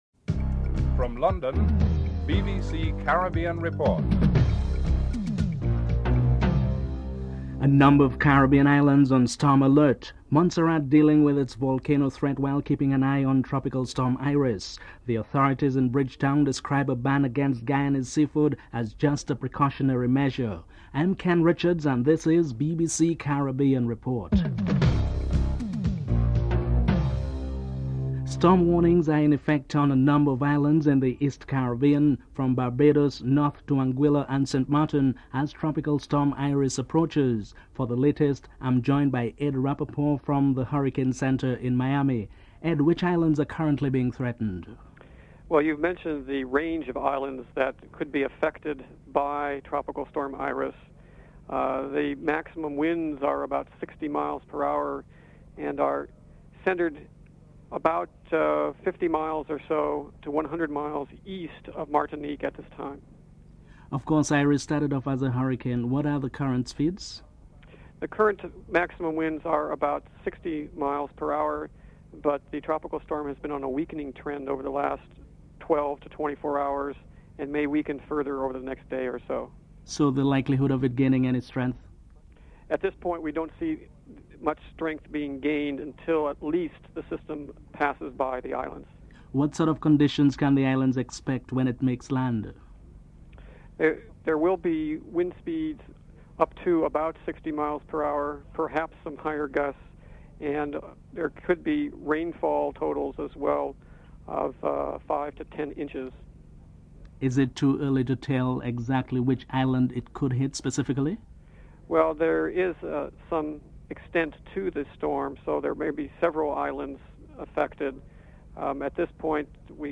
10. Recap of top stories (14:43-15:05)